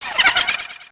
undertale muffet laugh Meme Sound Effect
undertale muffet laugh.mp3